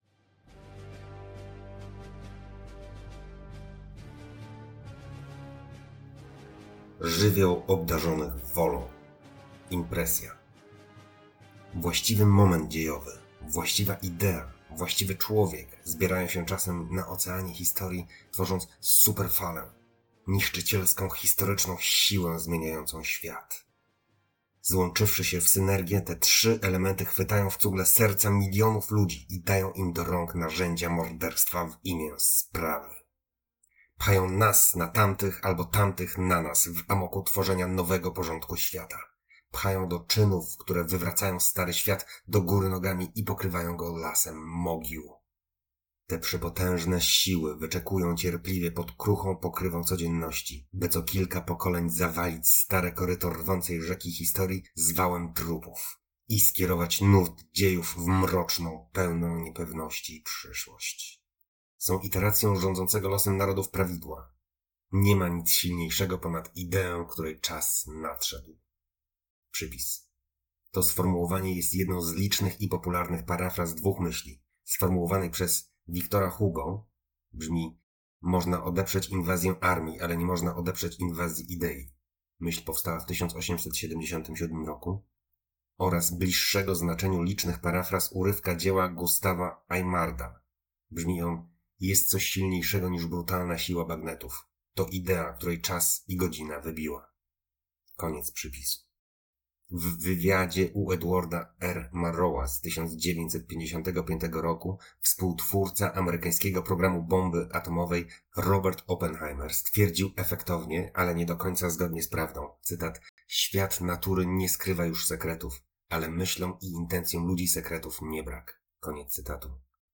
Audiobook: Chiny 一 Pulsujący matecznik cywilizacji.